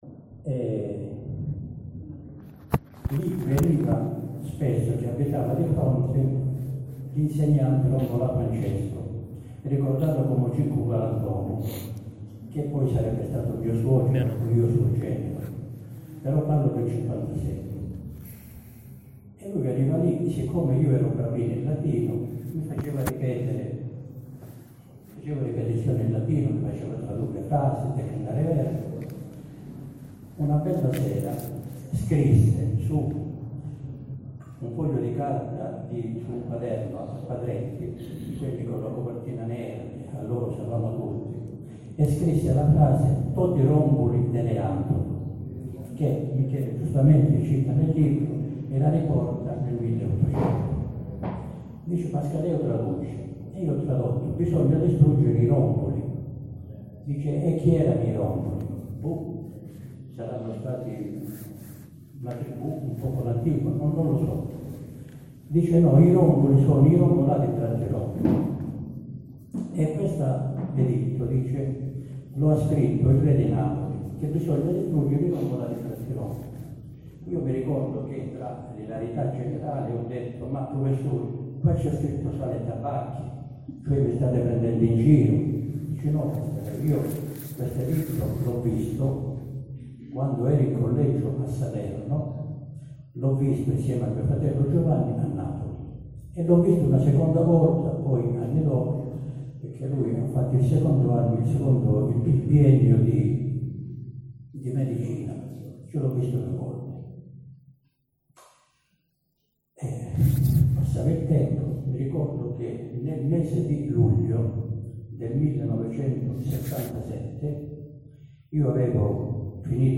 Parla un quarto d’ora. Racconta la vicenda legata allo storico editto del 1300 “Toti Rombuli Delenatur”. Dal tavolo dei relatori registro l’intervento.
Qualità non è eccelsa, magari adoperate cuffie: